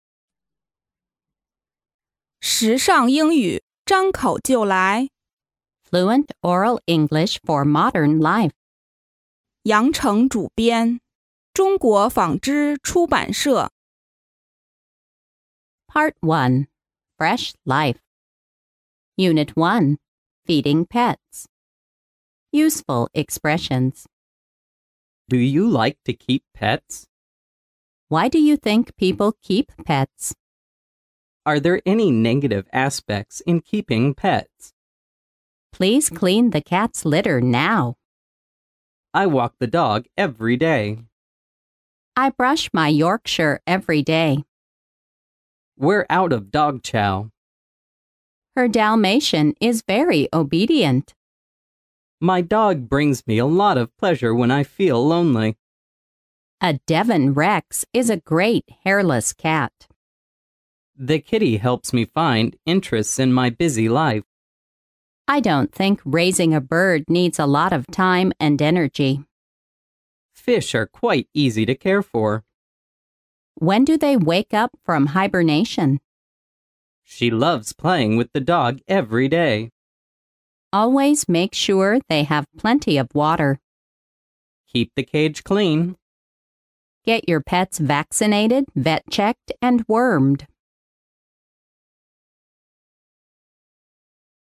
潮流英语情景对话张口就来Unit1：领养流浪狗mp3